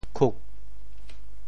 倔 部首拼音 部首 亻 总笔划 10 部外笔划 8 普通话 jué juè 潮州发音 潮州 kug4 文 潮阳 kug4 澄海 kug4 揭阳 kug4 饶平 kug4 汕头 kug4 中文解释 倔 <形> (形聲。